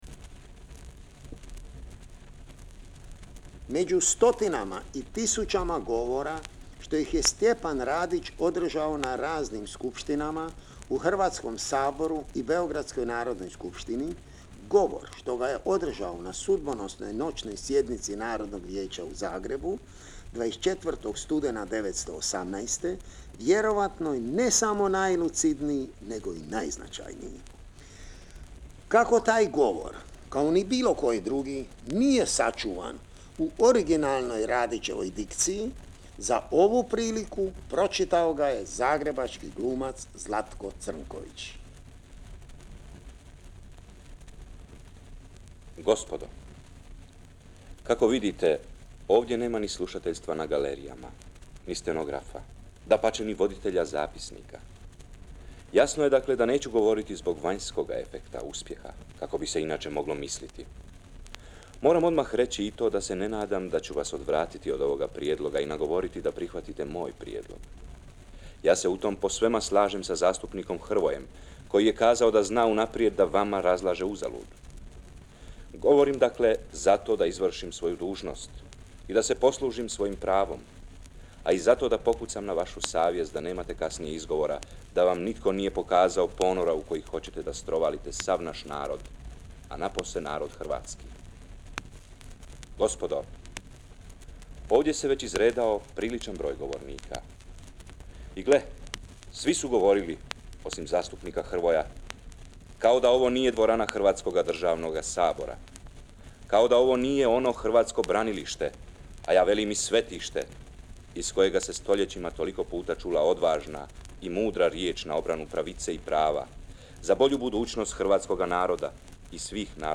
stjepan-radic-side-1-govor.mp3